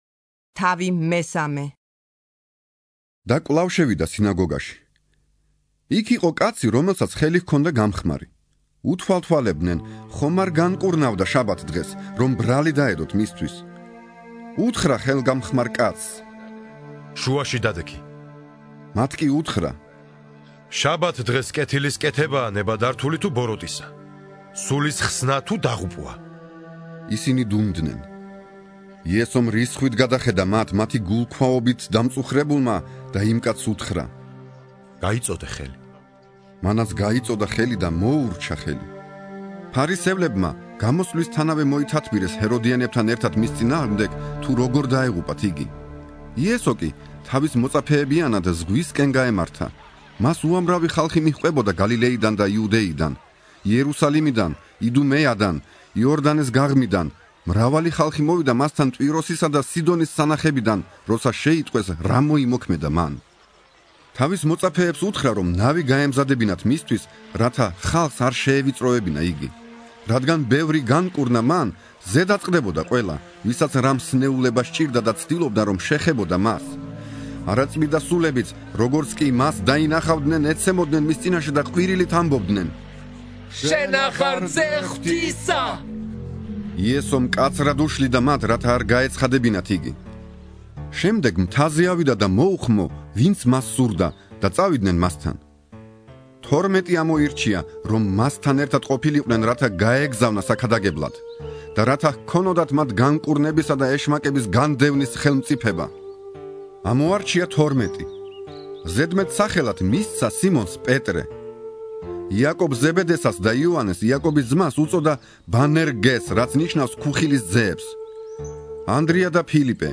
(41) ინსცენირებული ახალი აღთქმა - მარკოზის სახარება